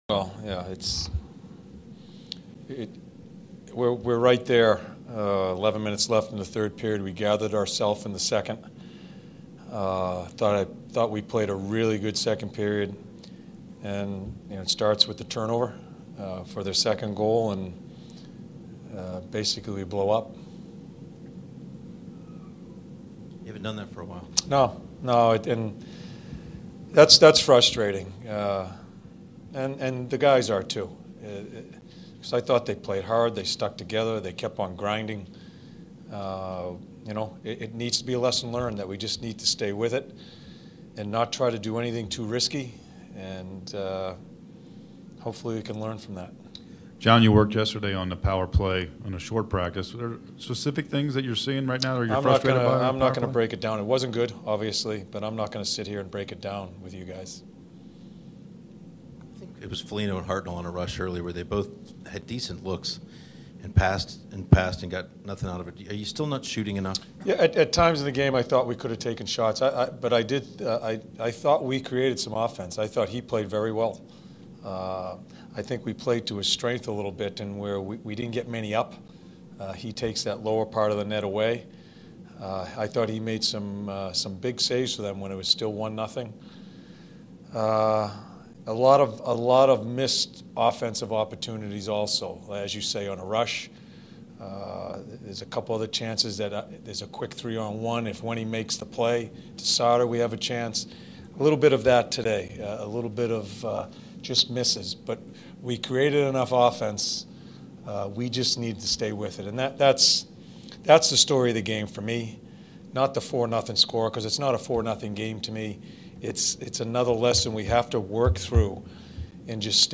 John Tortorella Post Game Press Conference 3-13-16